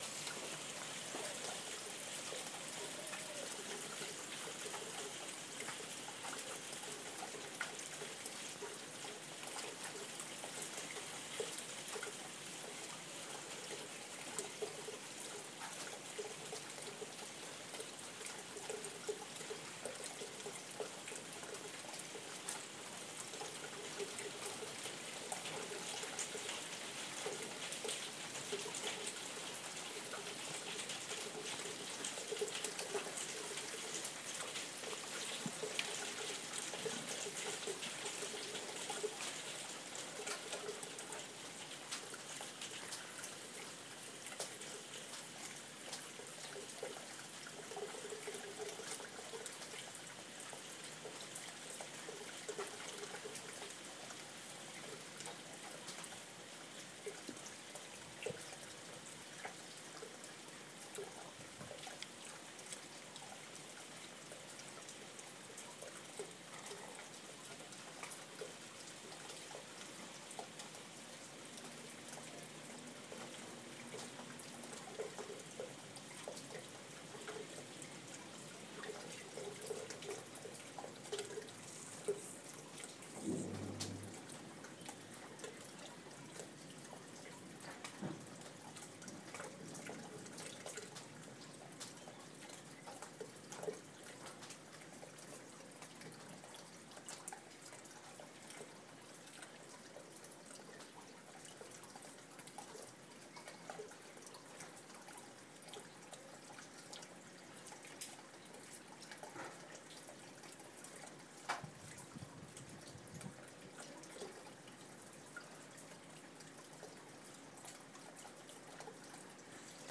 Steady rainfall...
Steady rainfall after a brief period of lightning and thunder. 28.03.14.